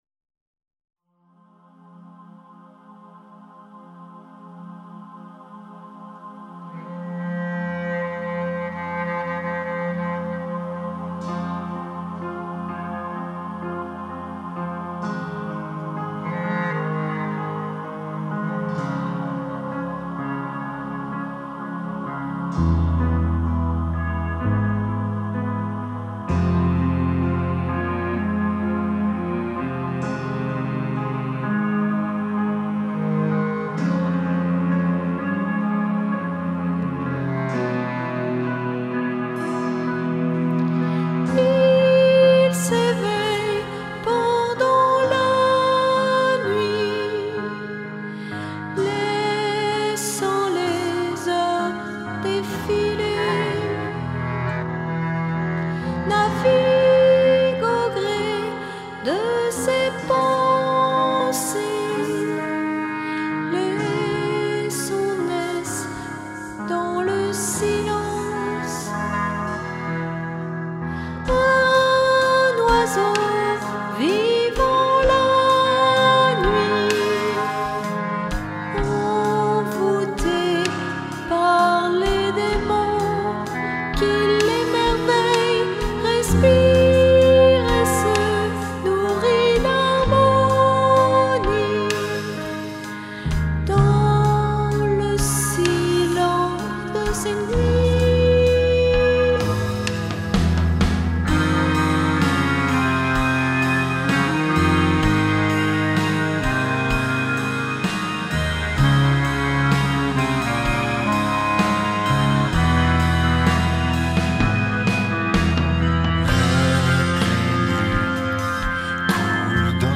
clarinette basse